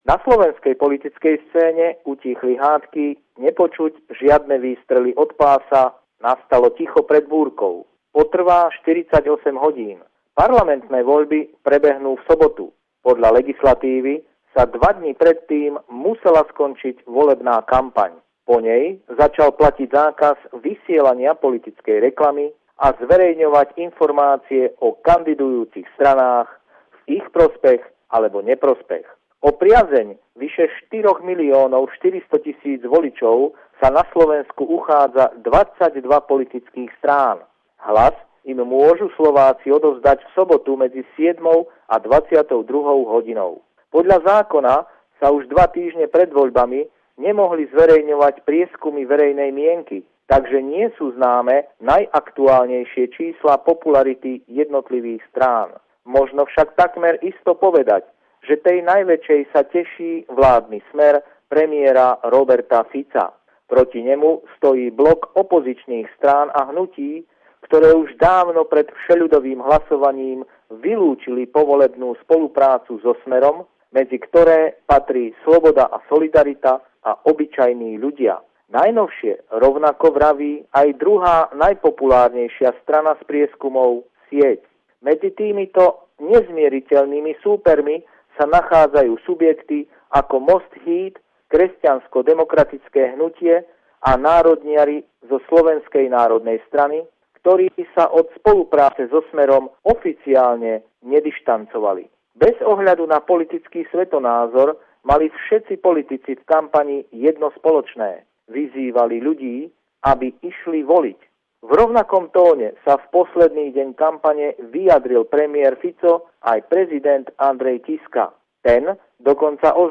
Pravidelný telefonát týždňa nášho bratislavského kolegu deň pred parlamentnými voľbami 2016